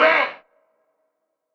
Vox 3 [ bounce ].wav